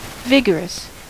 Ääntäminen
US Tuntematon aksentti: IPA : /ˈvɪɡəɹəs/